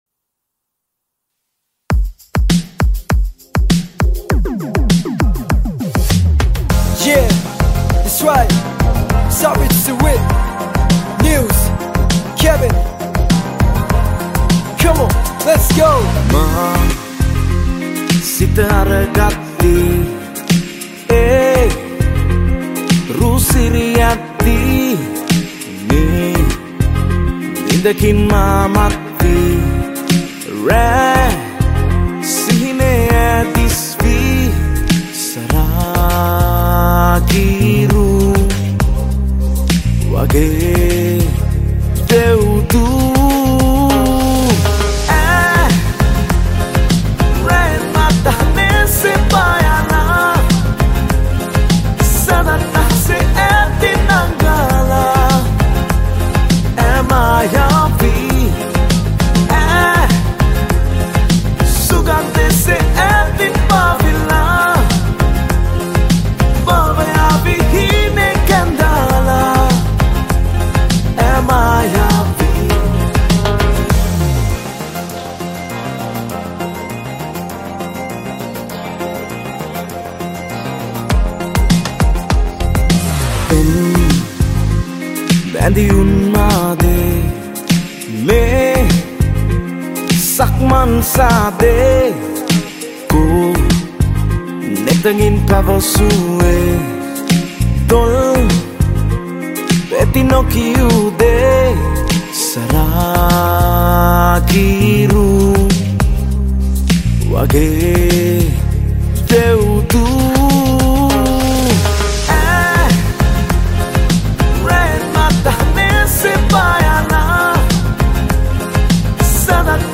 Additional Synth & Bass